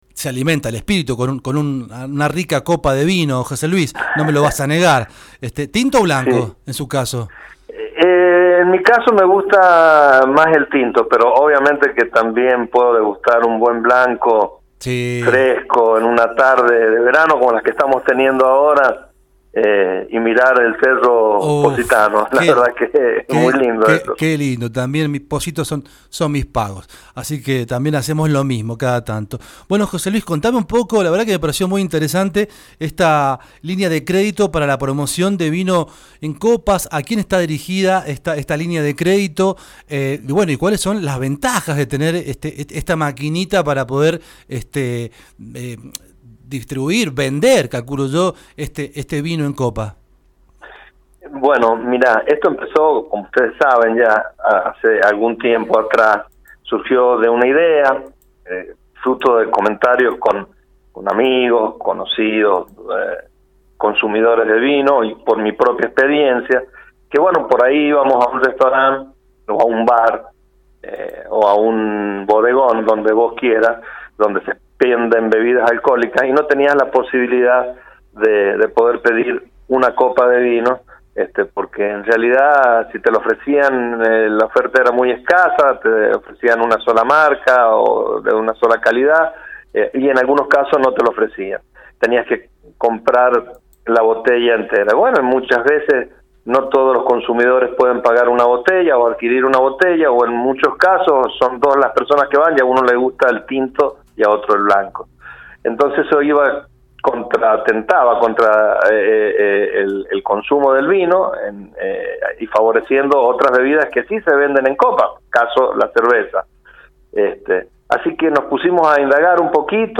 El diputado provincial José Luis Esteve, autor del proyecto que se convirtió en Ley sobre la Venta de vino fraccionado en copas, mantuvo una entrevista en el aire de Por Lo Menos donde dio detalles de las líneas de crédito que el Ministerio de Producción.